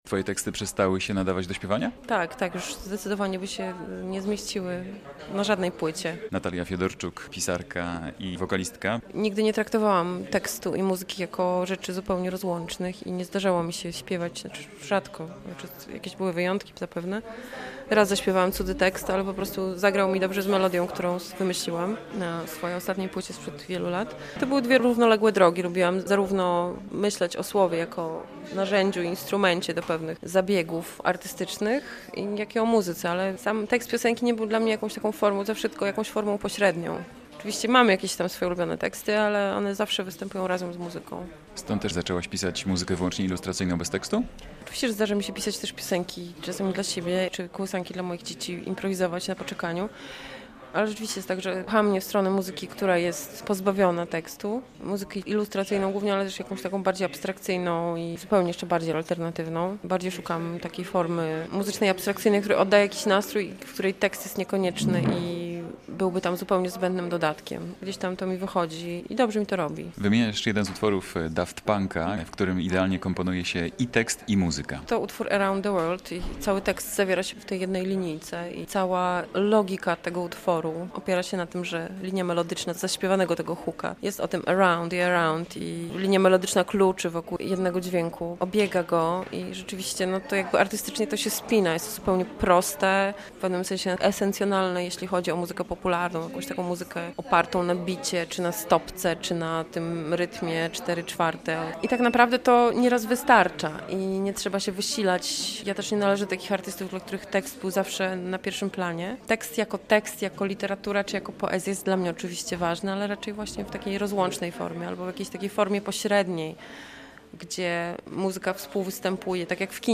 O pisaniu tekstów piosenek cz.2 - rozmowa
Dyskutowano o tym niedawno na festiwalu Halfway w Białymstoku.